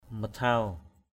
/mə-tʱaʊ/ aiek: masao m_s<